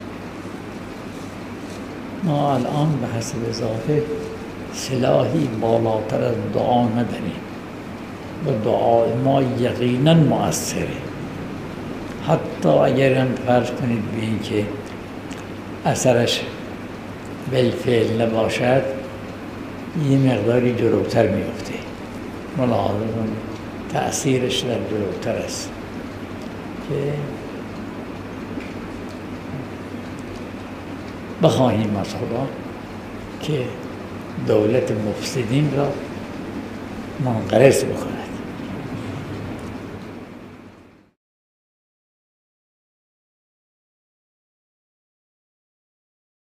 به گزارش پایگاه خبری 598، مرحوم آیت الله بهجت در حاشیه درس خارج به موضوع «اثرات دعا» اشاره کردند که تقدیم شما فرهیختگان می شود.